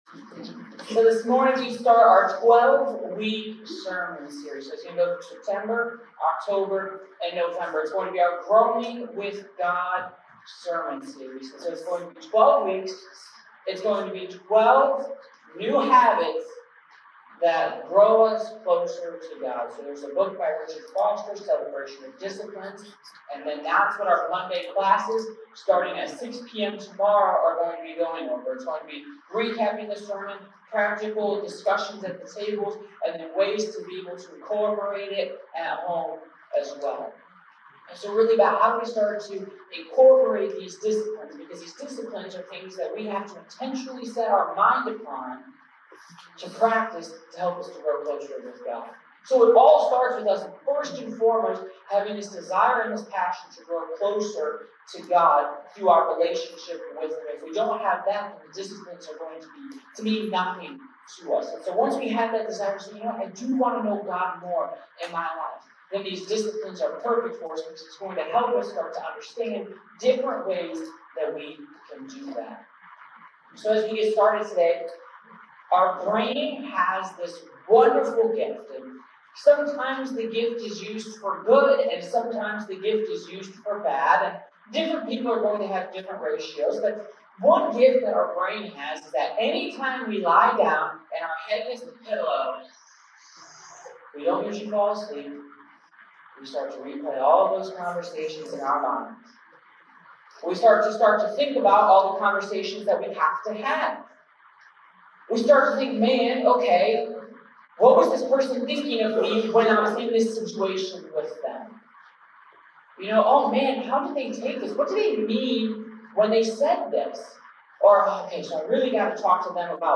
(Sound may not be clear in some areas. This sermon was recorded before our equipment upgrade.)